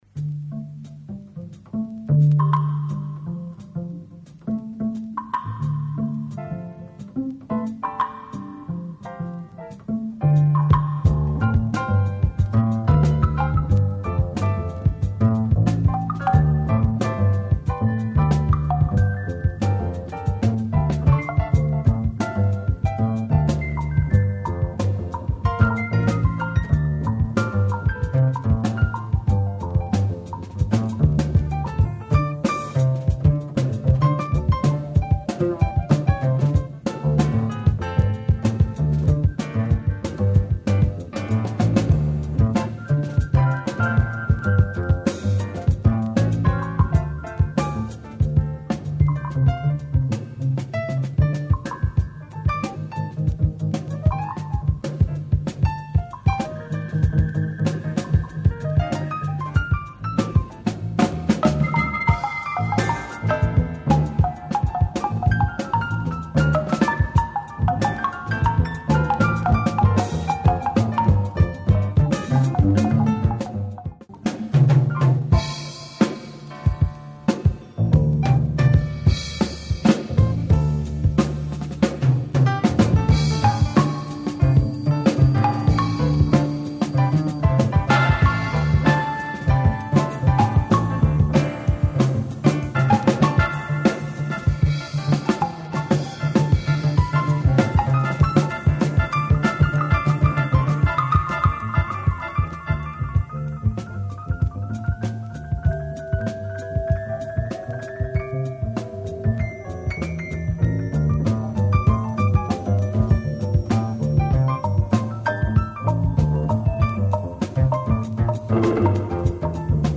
Superb avant-garde / experimental jazz album
Pure abstract hip hop beats !